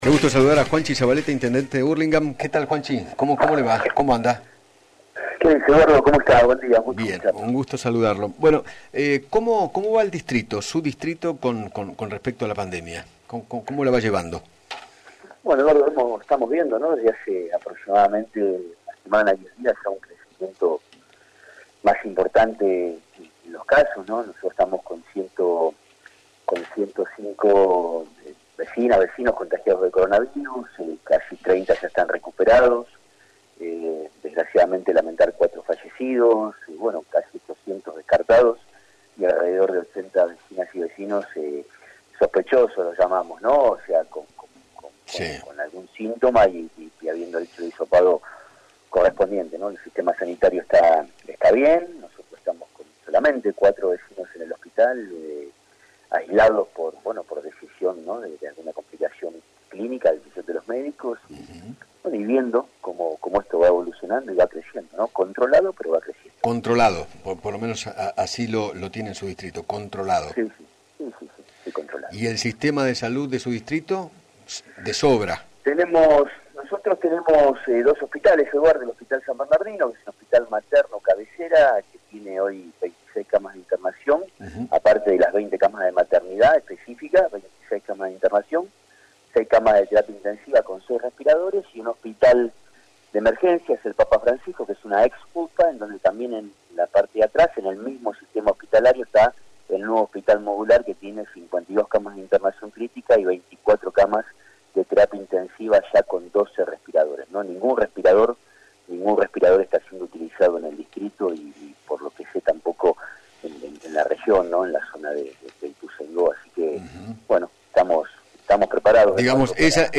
Juan Zabaleta, Intendente de Hurlingham, dialogó con Eduardo Feinmann sobre la intervención de Vicentín. Además, se refirió a la situación del Coronavirus en su localidad y aseguró que, “por el momento, ningún respirador está siendo utilizado en el distrito ni en toda la región”.